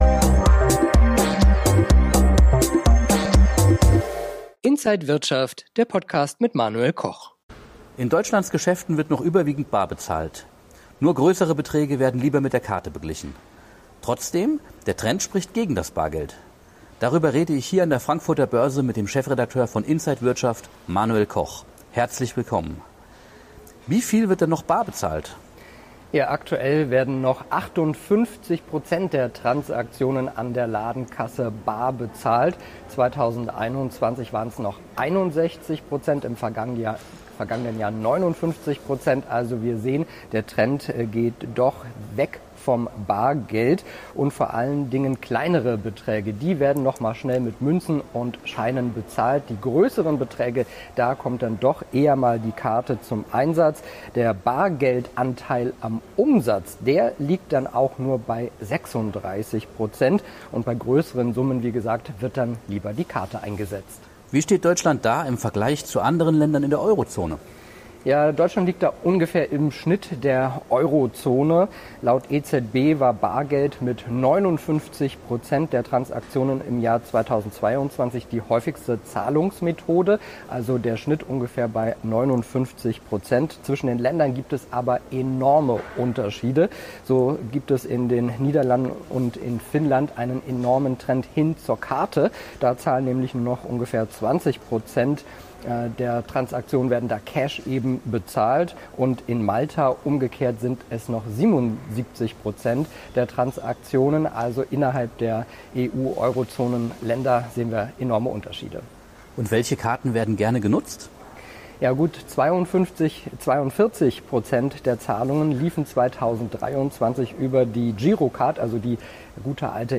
Alle Details im Interview von der